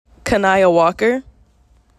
Pronunciations